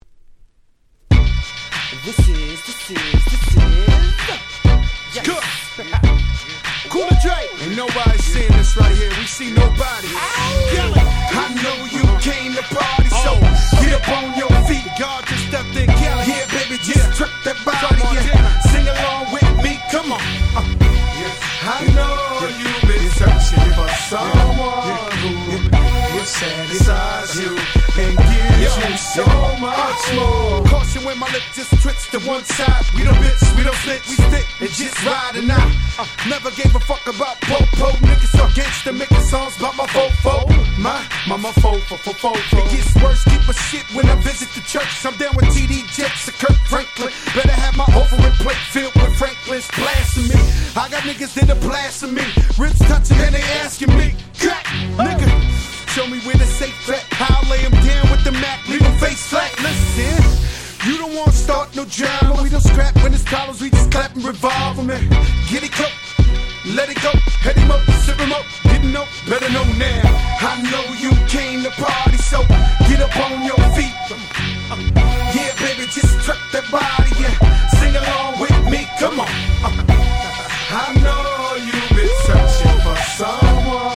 05' Big Hit Hip Hop !!